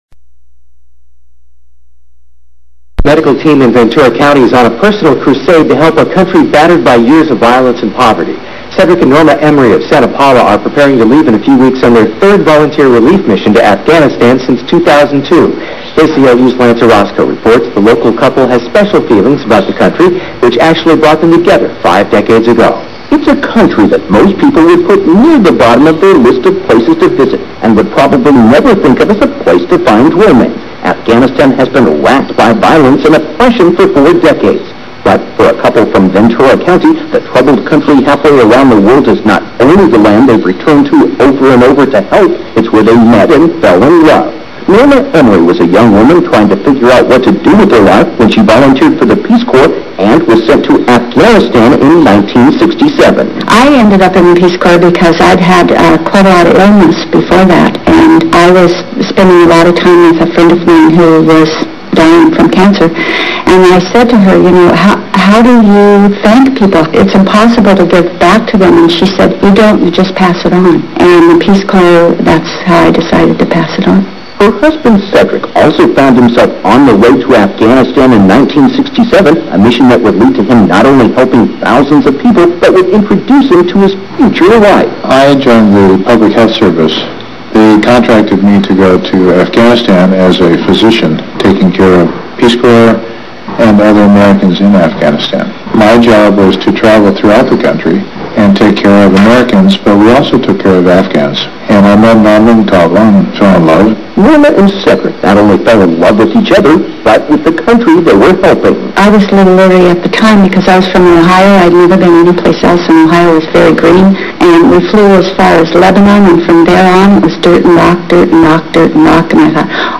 radio interview on NPR!